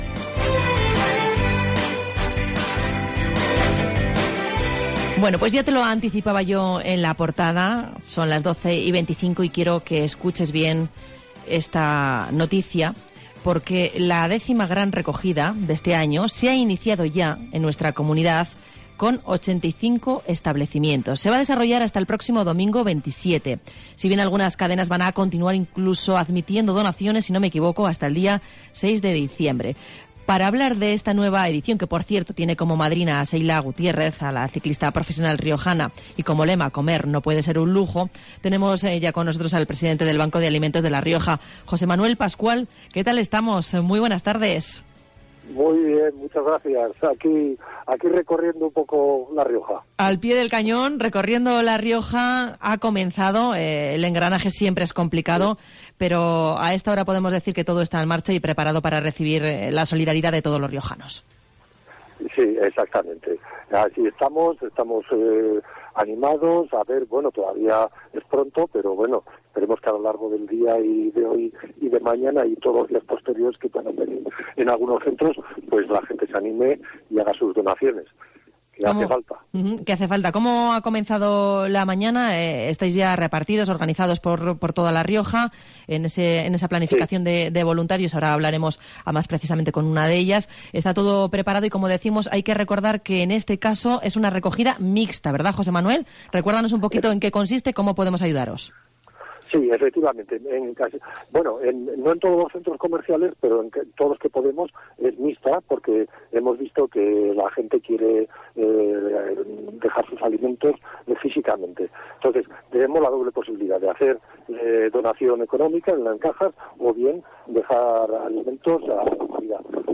Herrera en COPE se traslada hasta una caja de supermercado para contarte cómo se desarrolla la X Gran Recogida del Banco de Alimentos en La Rioja que recoge alimentos y dinero